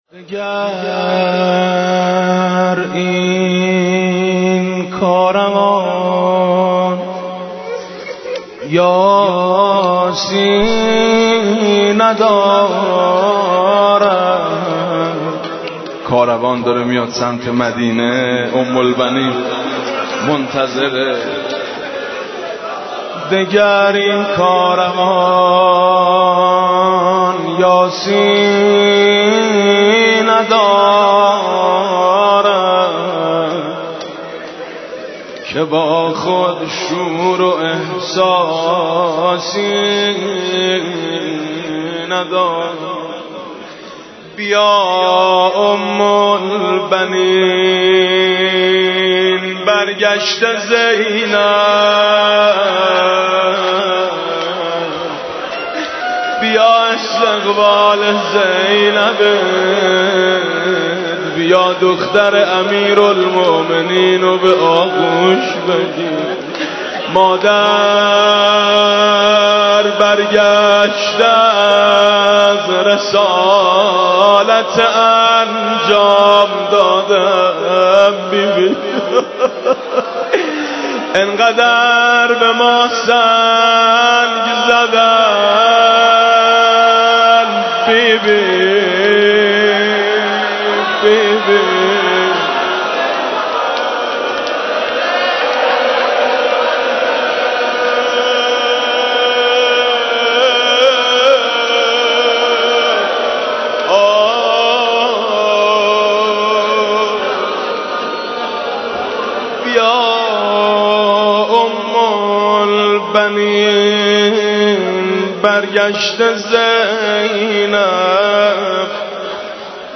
روضه: چه شد در کربلا هستی زهرا حسینم